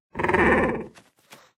Minecraft Version Minecraft Version snapshot Latest Release | Latest Snapshot snapshot / assets / minecraft / sounds / mob / sniffer / happy2.ogg Compare With Compare With Latest Release | Latest Snapshot
happy2.ogg